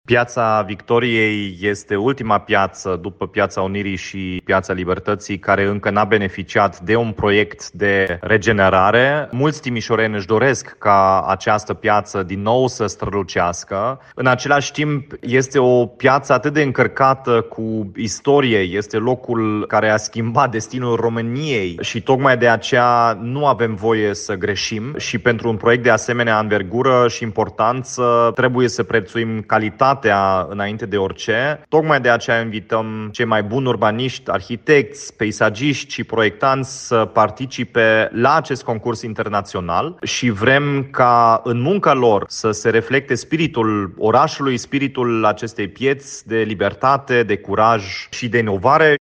Primăria Timișoara, prin Ordinul Arhitecților, lansează un concurs internațional de soluții la care sunt așteptați să participe arhitecți din toată Europa, anunță primarul Dominic Fritz.